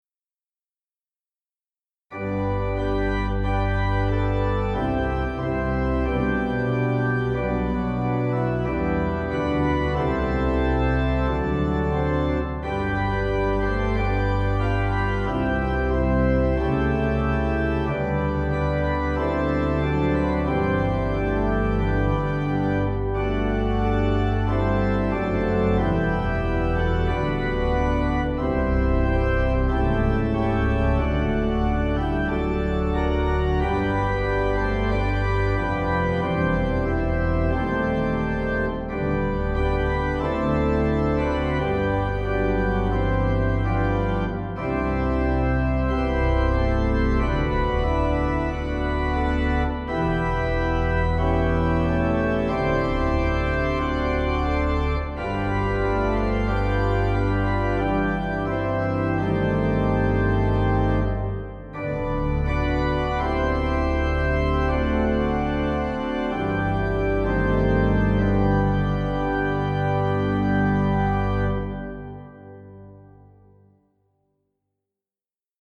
harmonization of the hymn tune
Hymn Harmonizations